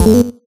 Cancel2.ogg